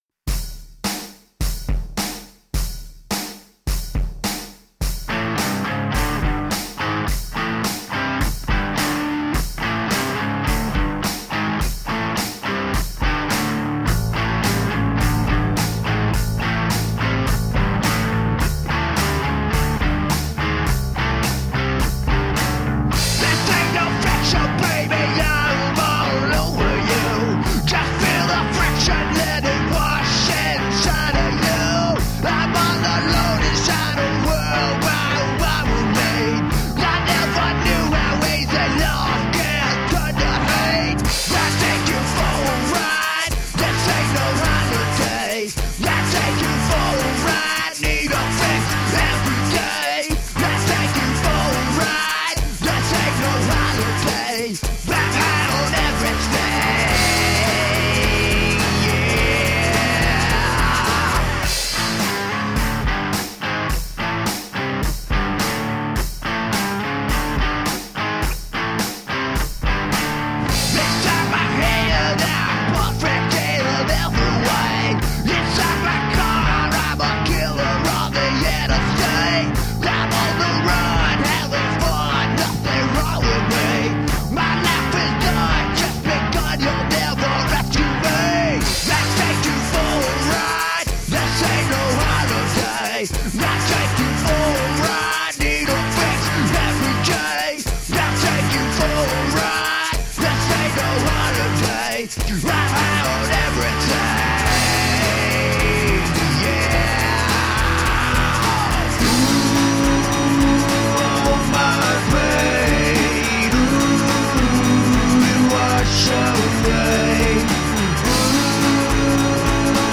High energy rock and roll.
Tagged as: Hard Rock, Metal, Punk, High Energy Rock and Roll